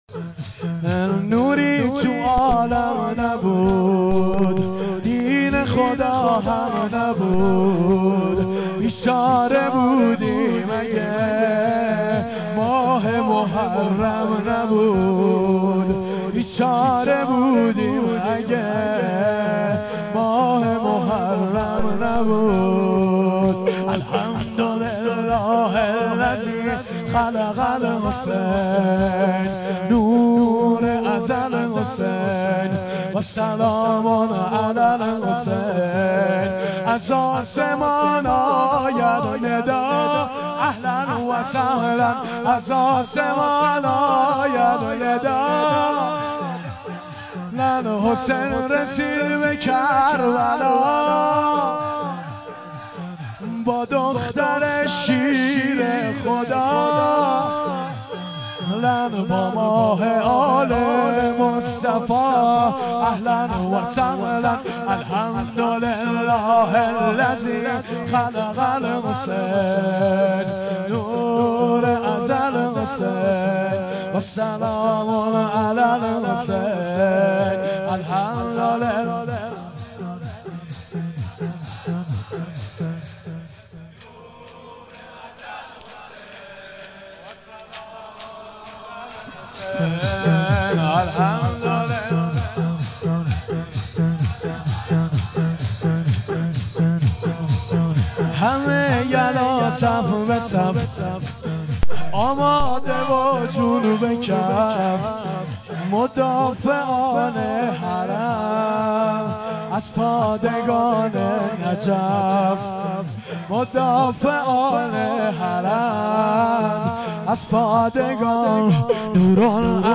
شور
شب دوم محرم ۱۴۴۱